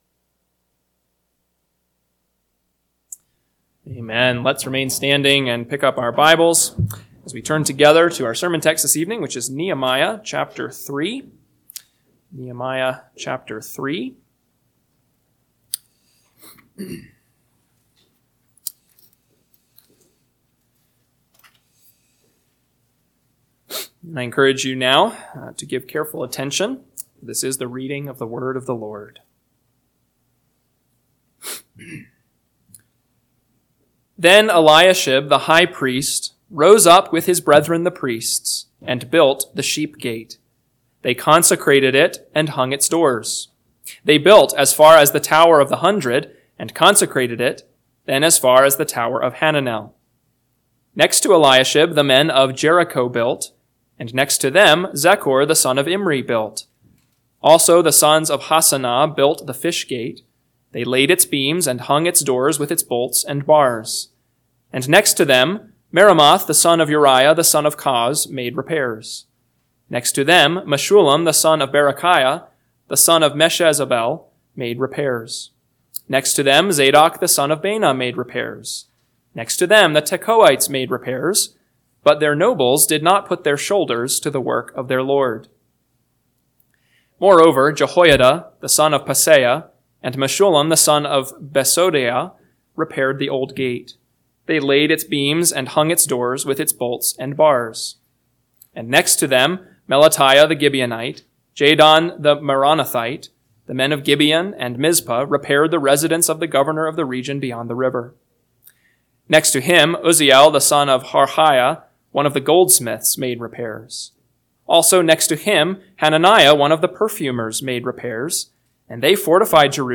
PM Sermon – 7/6/2025 – Nehemiah 3 – Northwoods Sermons